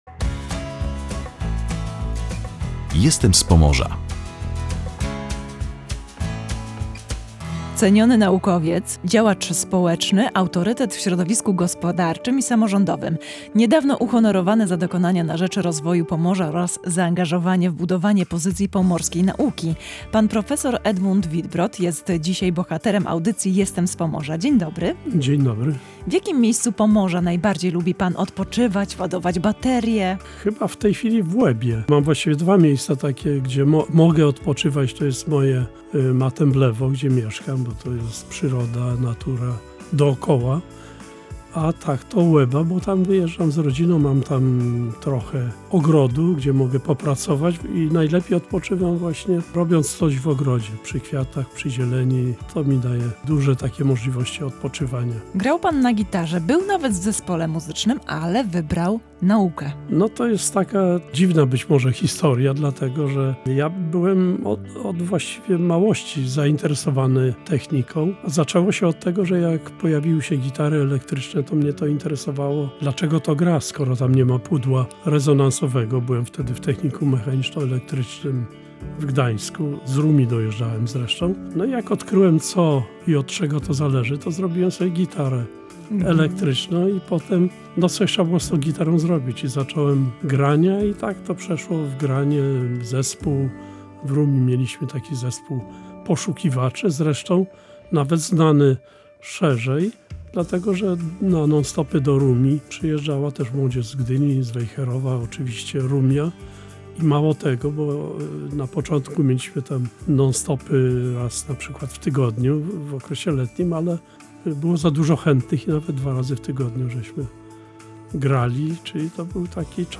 Niedawno uhonorowany za dokonania na rzecz rozwoju Pomorza oraz zaangażowanie w budowanie pozycji pomorskiej nauki. Bohaterem audycji „Jestem z Pomorza” był profesor Edmund Wittbrodt.